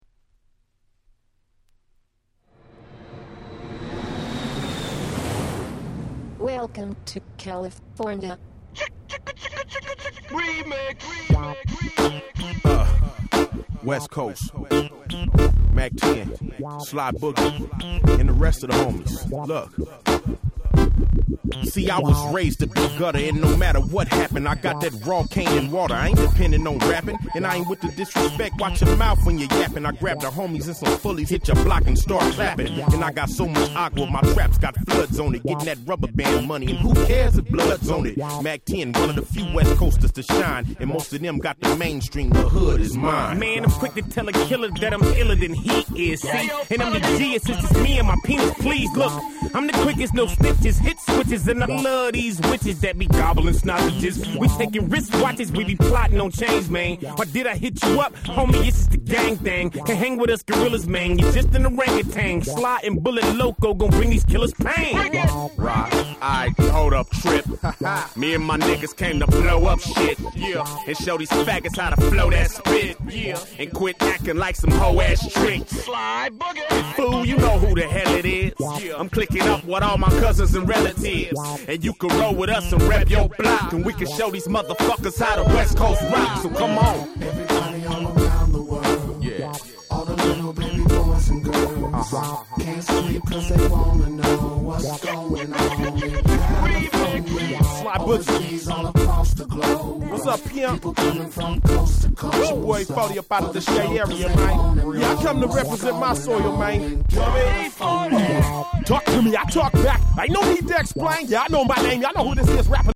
03' Smash Hit West Coast Hip Hop Classics.